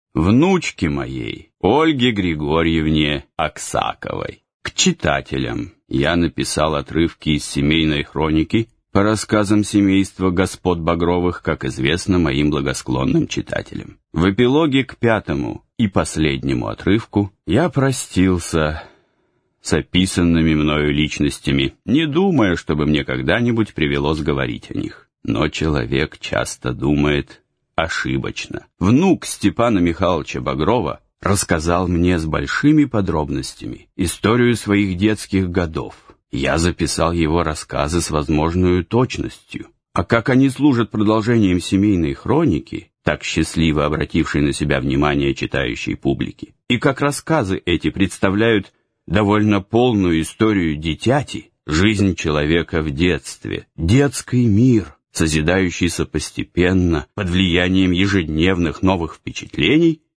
Аудиокнига Детские годы Багрова-внука | Библиотека аудиокниг